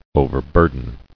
[o·ver·bur·den]